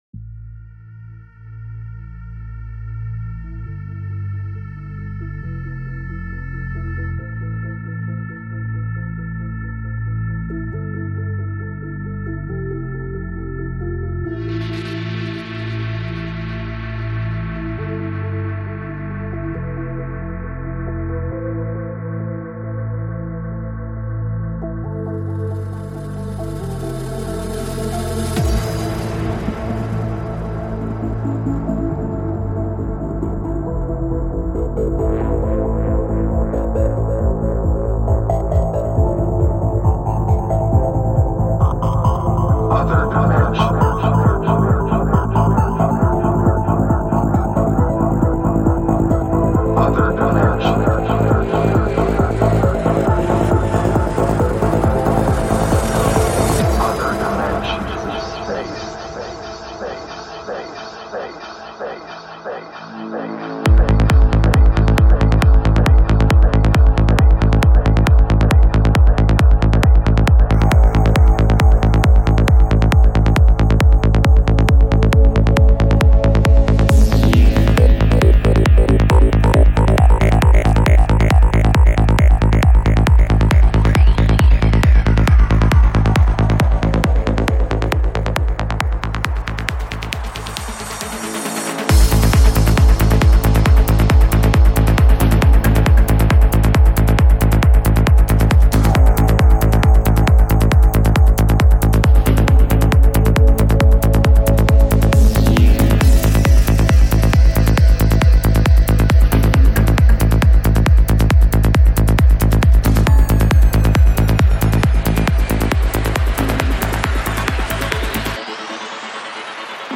Жанр: Goa Trance